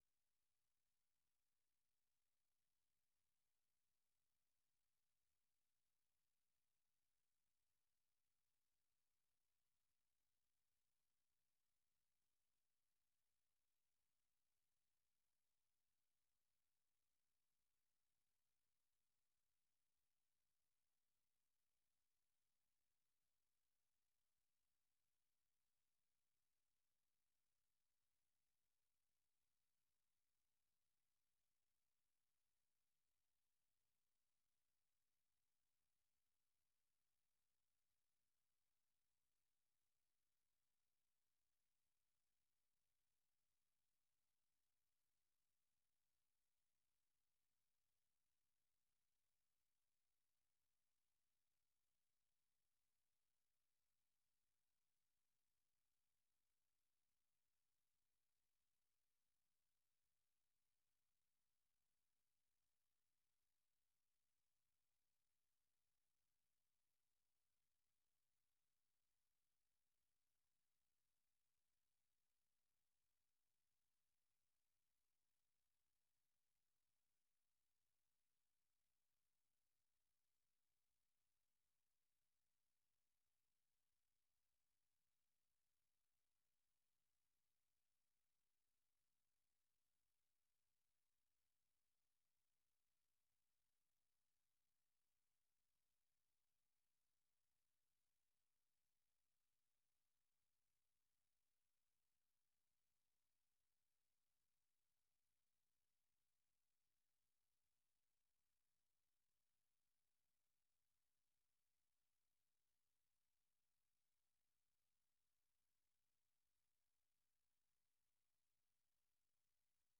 در برنامۀ روایت امروز شرح وضعیت در افغانستان را از زبان شرکت کنندگان این برنامه می‌شنوید. این برنامه شب‌های یک‌شنبه، دوشنبه، سه‌شنبه و پنج‌شنبه از ساعت ٩:۰۰ تا ۹:۳۰ شب به گونۀ زنده صدای شما را در رادیو و شبکه‌های ماهواره‌ای و دیجیتلی صدای امریکا پخش می‌کند.